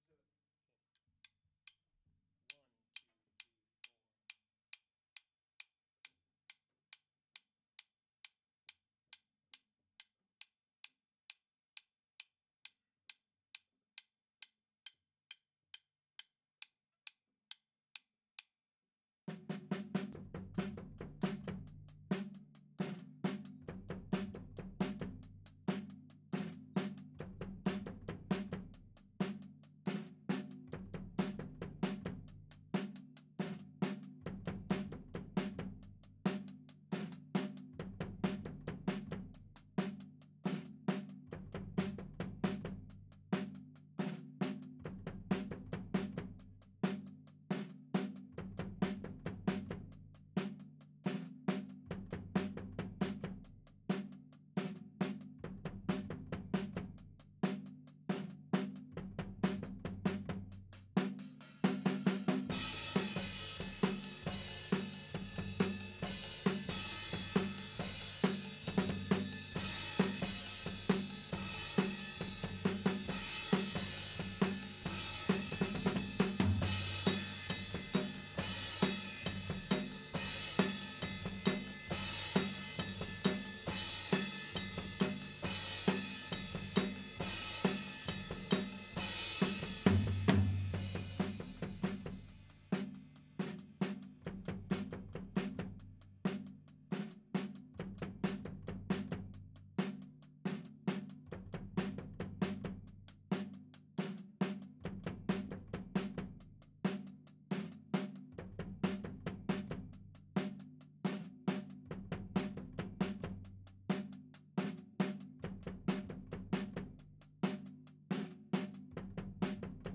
Faith-Mid Tom (2.1).wav